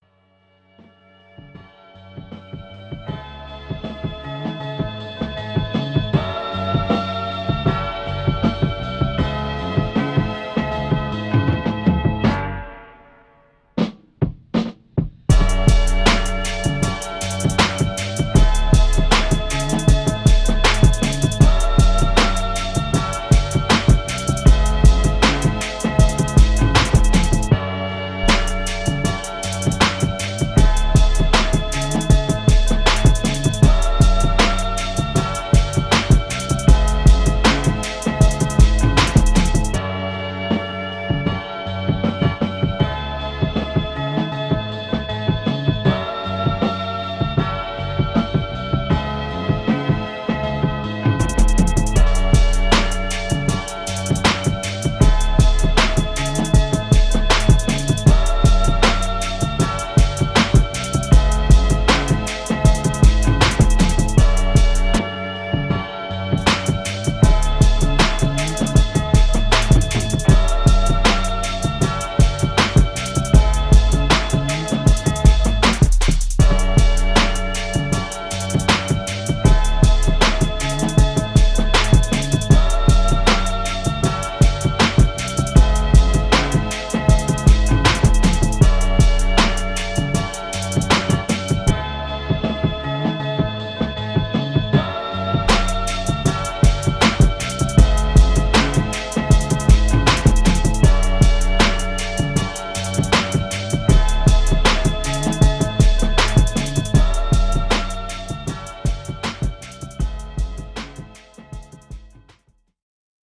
Atlanta undgerground sound
instrumental album